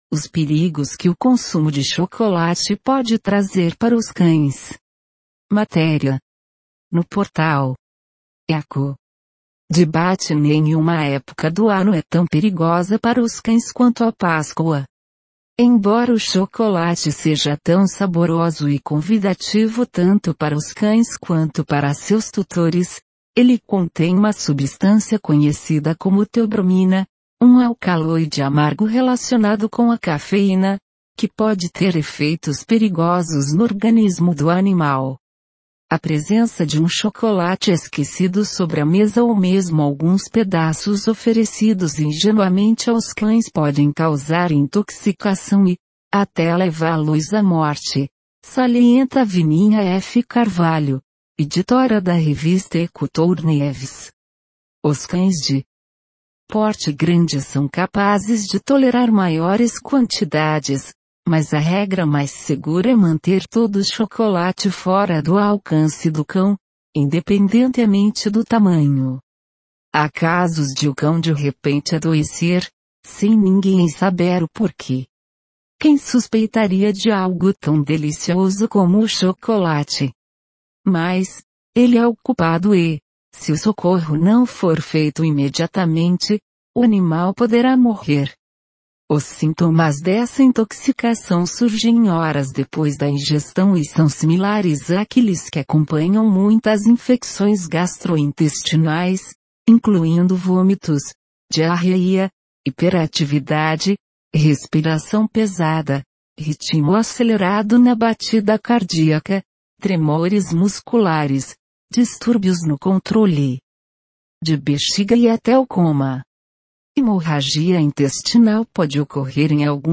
Sinopse em áudio, mp3, da matéria “Os perigos que o consumo de chocolate pode trazer para os cães”